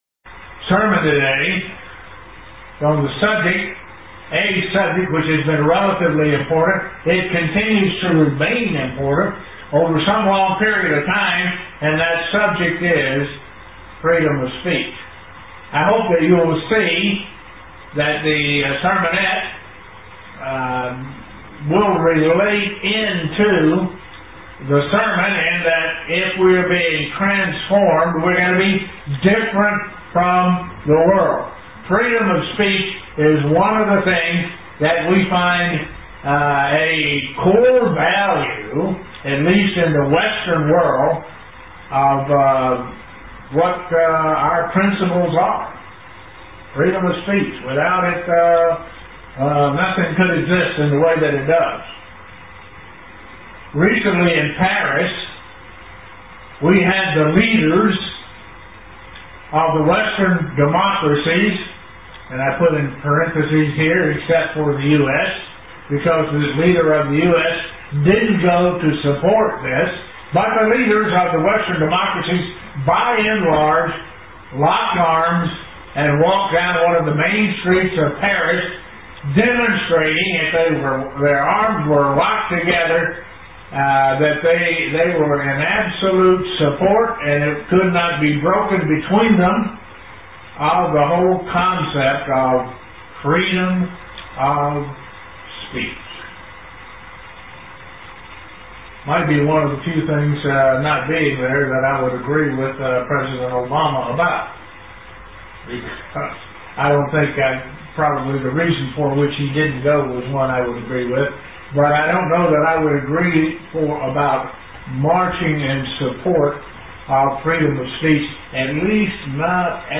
How to control your speech UCG Sermon Studying the bible?